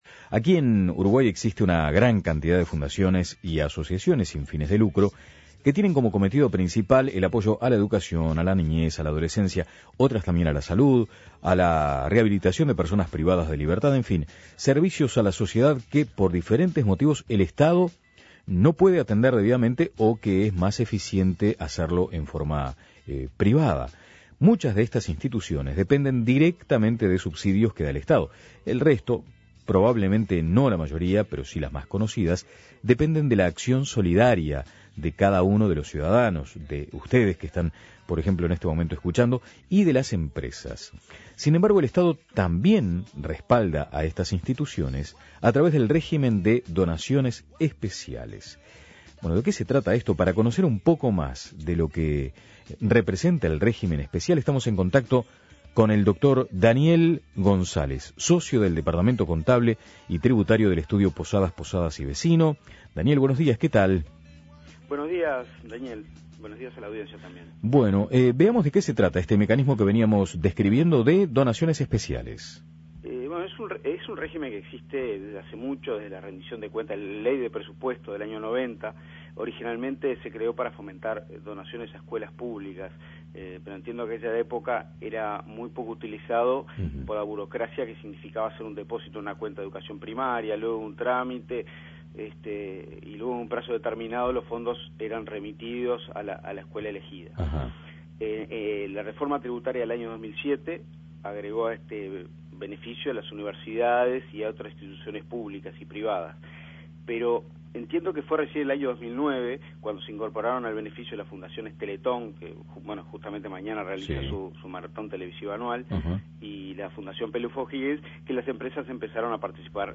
Análisis Posadas, posadas y Vecino